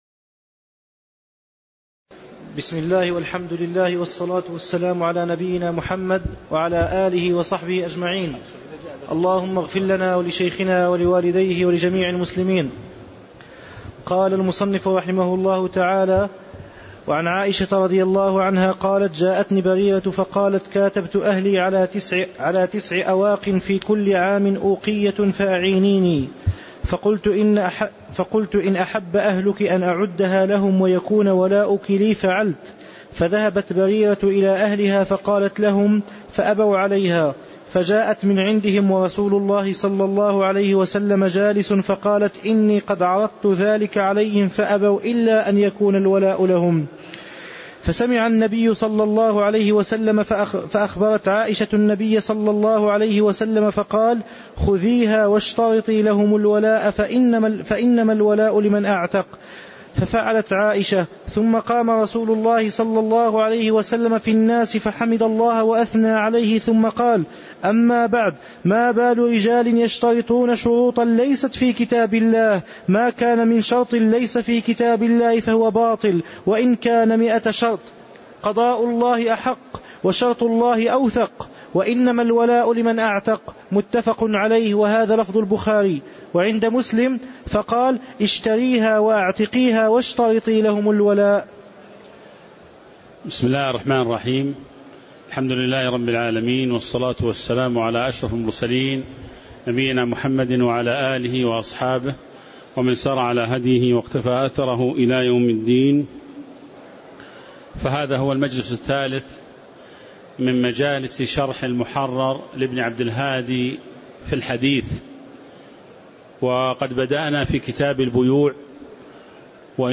تاريخ النشر ٦ جمادى الأولى ١٤٤٦ هـ المكان: المسجد النبوي الشيخ